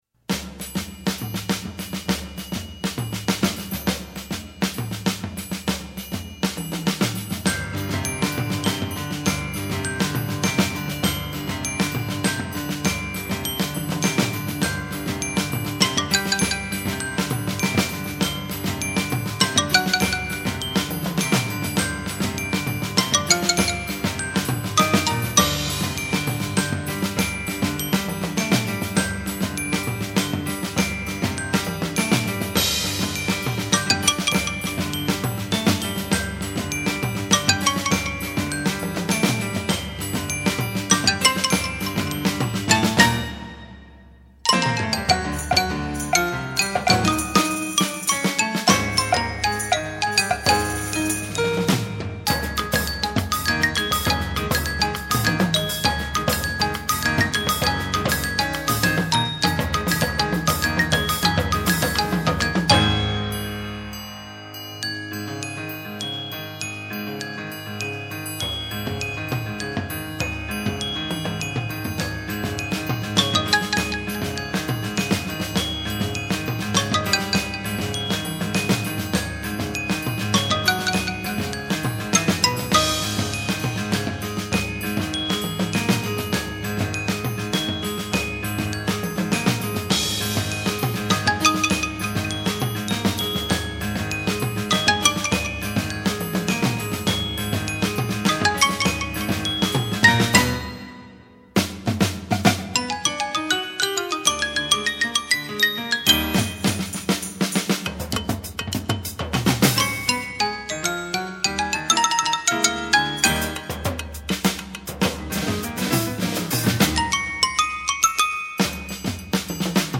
Voicing: Percussion Sextet